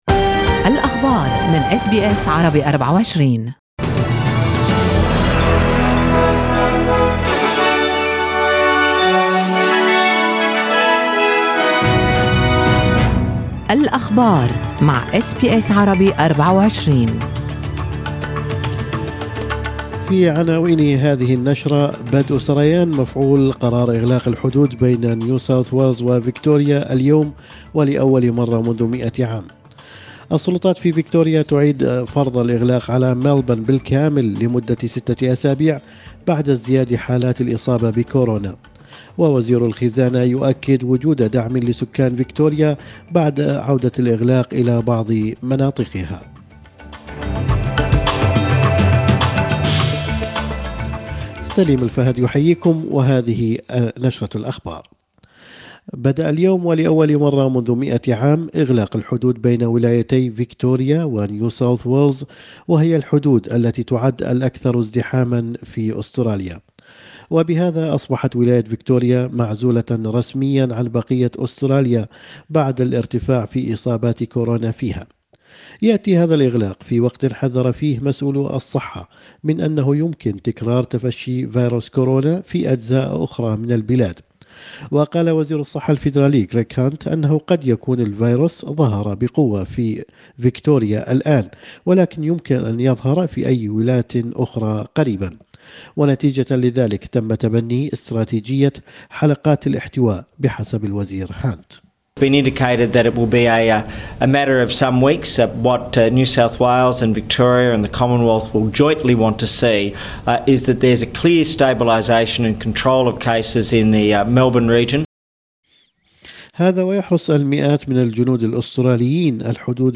نشرة أخبار الصباح 8/7/2020